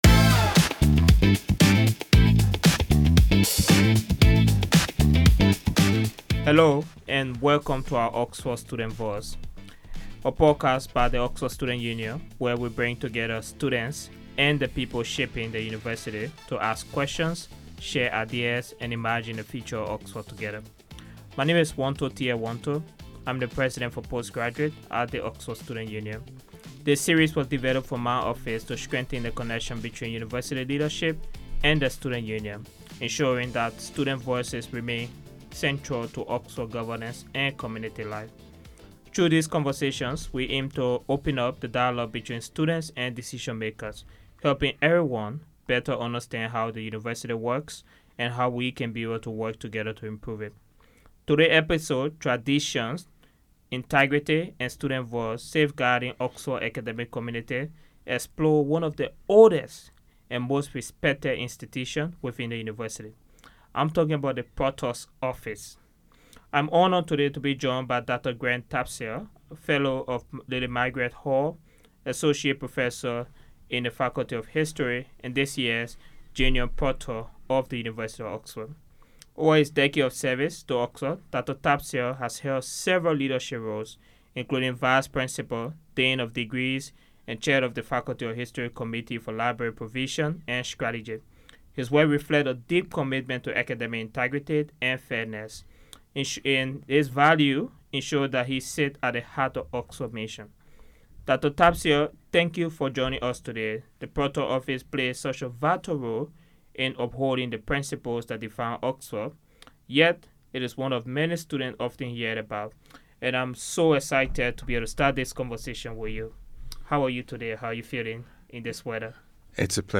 The conversation explores how academic integrity, due process, and fairness are upheld across examinations, student conduct, and institutional governance within Oxford’s unique collegiate structure.